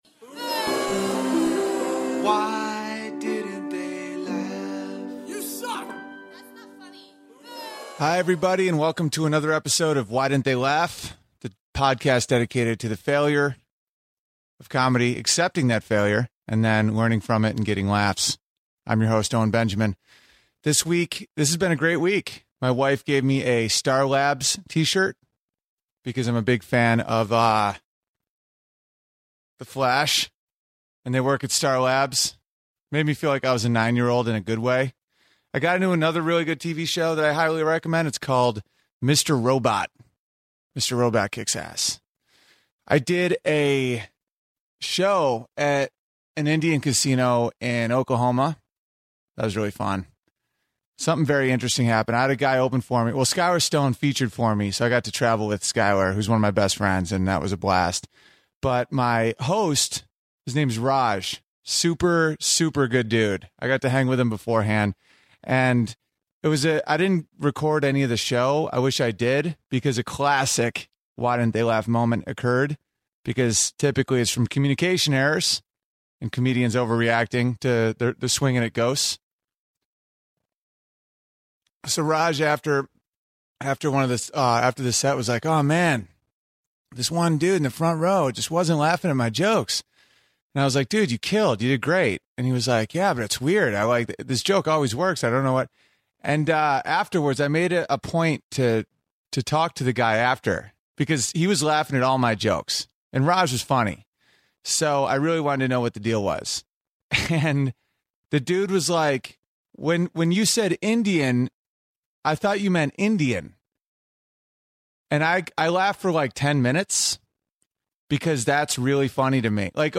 This episode I tell the story of an Indian that was mistaken for an Indian, I realize I hate Rick Springfield, and I take requests on piano.